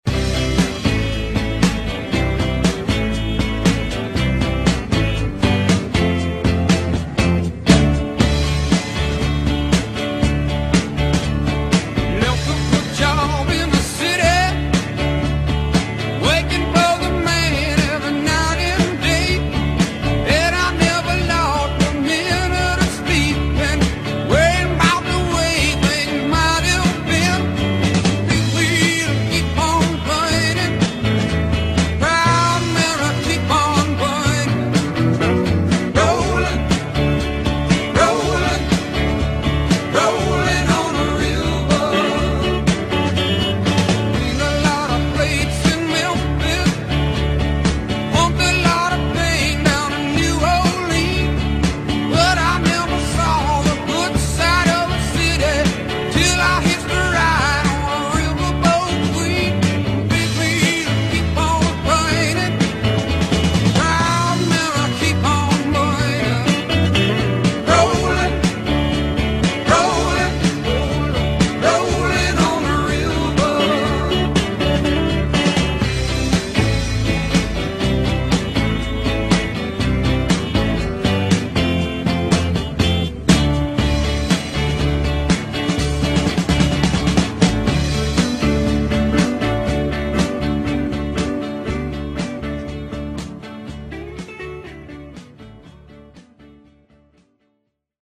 vi ricordiamo che è tra le basi femminili
facendo leva su un ritmo febbricitante